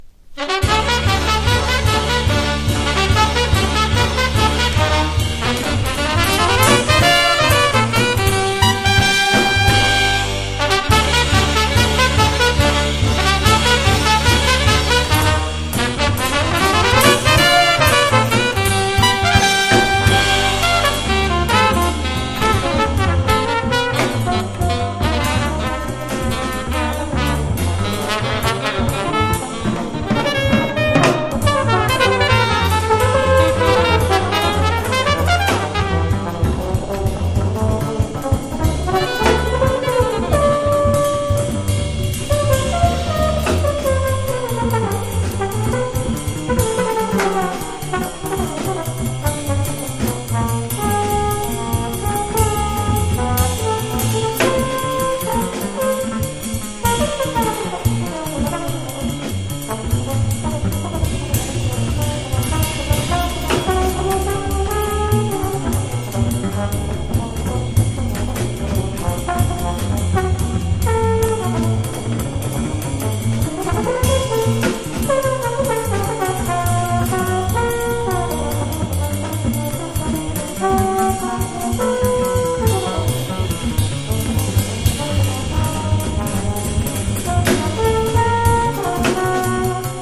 # MODERN JAZZ# FREE / SPIRITUAL